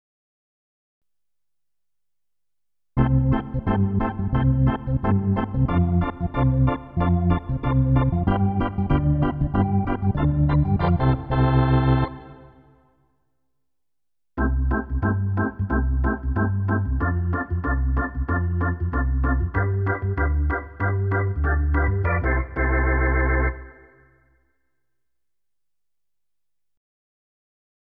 In den folgenden Klangbeispielen spiele ich zuerst immer die Yamaha Genos Voice, danach die V3 YAMMEX Sounds.
Die Yamaha Vintage Orgel ist mit Effekten überladen und matscht etwas. Die Orgelsounds des V3 gibt es mit langsamem und schnellem Leslie-Effekt.